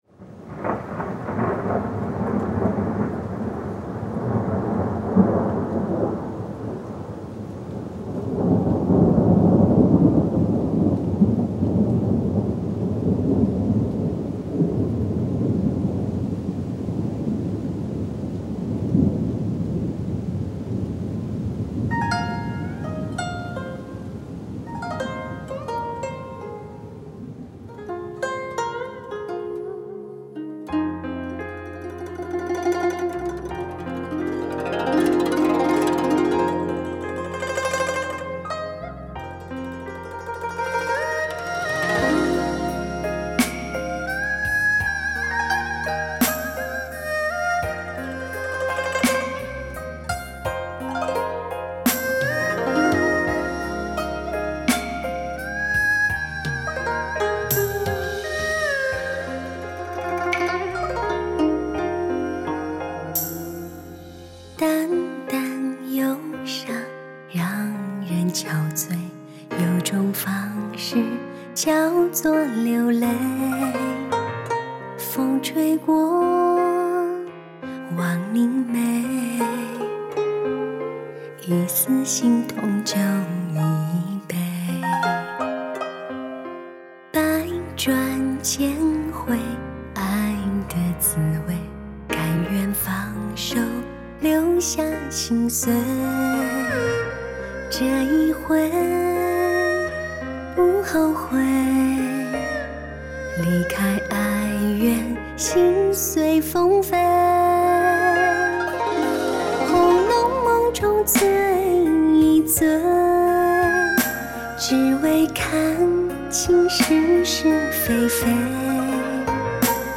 在甜美的歌声中，将自己融化，不去理会身边的灯红酒绿，无视归途中的人生嘈杂。